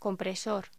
Locución: Compresor
voz